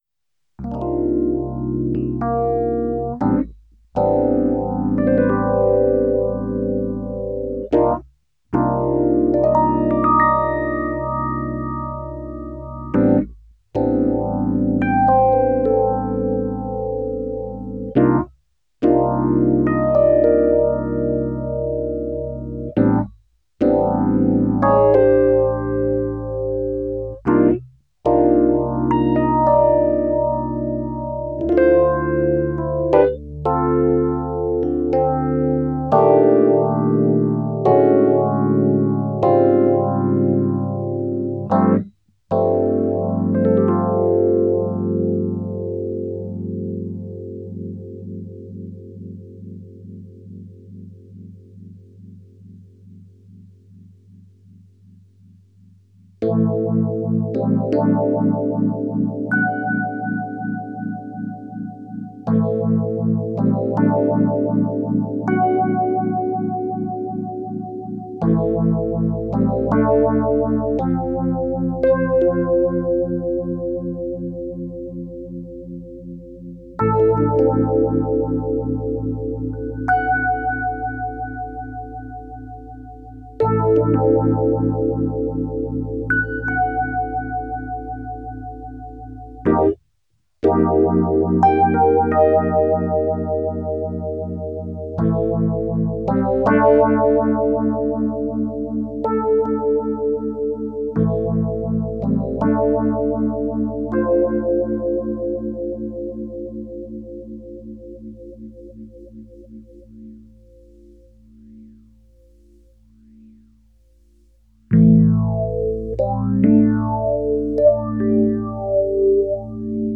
Rocktek PHR-01Rocktek PHR-01 Phaser. Ein Taiwan-Klon des Boss PH1r aus den 80ern, klingt aber wegen der höheren Abstimmung der Filter am Rhodes besser als das 'Original'.